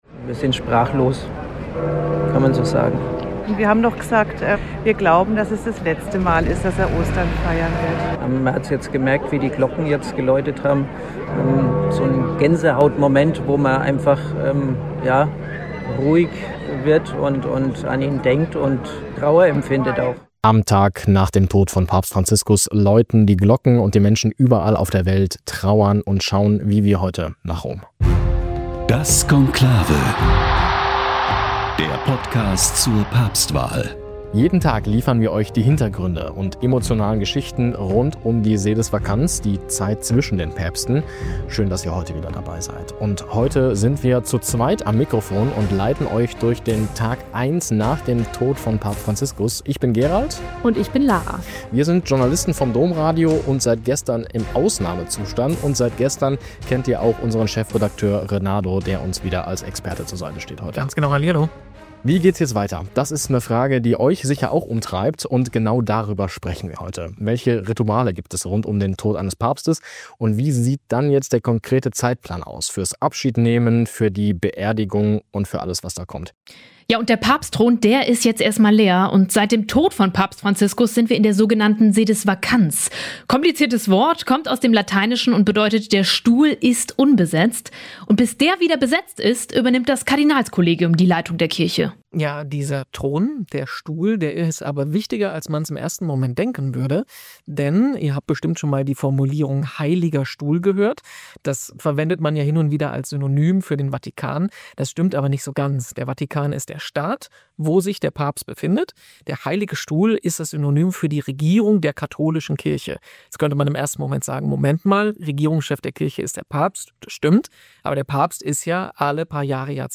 Ein täglicher Blick hinter die Kulissen, jenseits von Schlagzeilen: verständlich, spannend und authentisch moderiert.